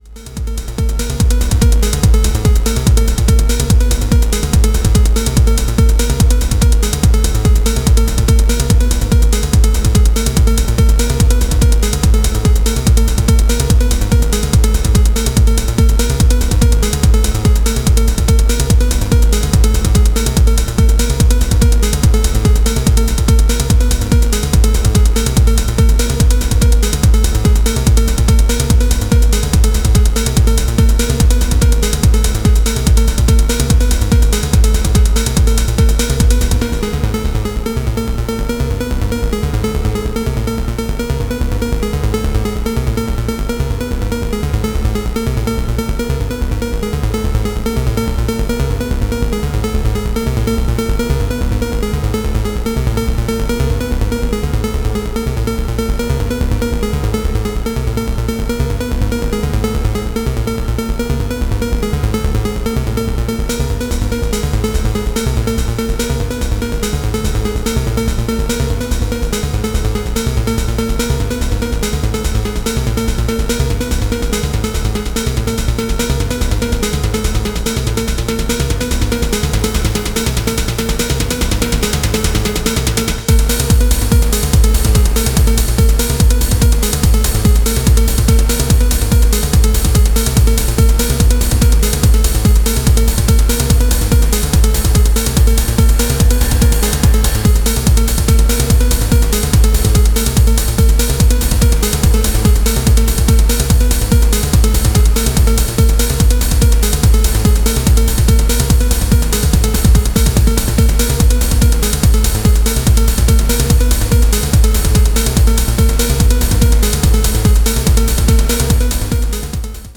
そわそわ周囲を気にしてしまう不穏リフが危ない